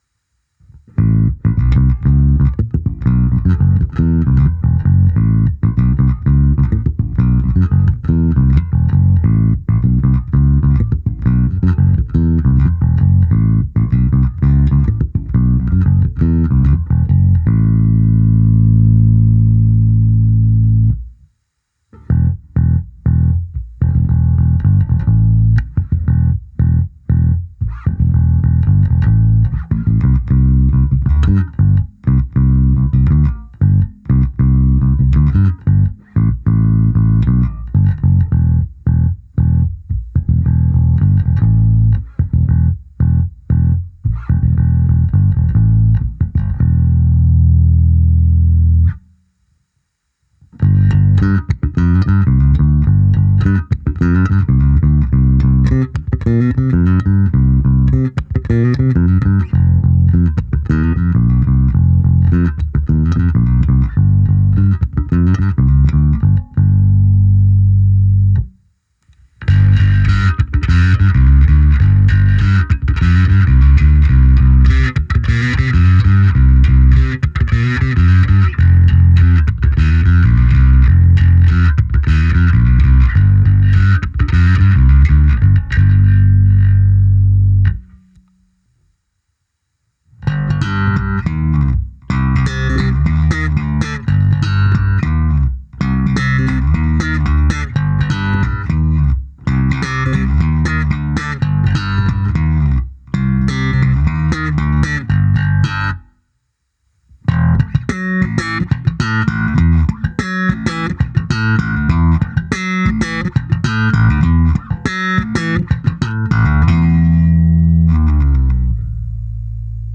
Pevný, hutný vrčák, kouše, kapelou se prořezává jak rozžhavený nůž máslem, ale přitom i tmelí, má ty správné středy.
Nahrávka se simulací aparátu, kompresorem a ekvalizací, kde bylo použito i zkreslení a hra slapem, na konci ještě speciální ukázka hry na struně H bez a se zkreslením.